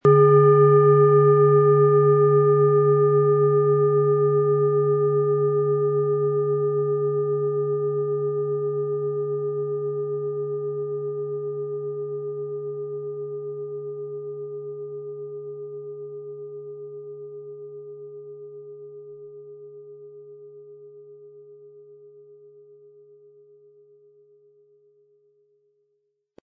Nach Jahrhunderte alter Tradition von Hand getriebene Planetenklangschale Pluto.Die Klangschalen lassen wir in kleinen Manufakturen machen.
• Mittlerer Ton: Uranus
HerstellungIn Handarbeit getrieben
MaterialBronze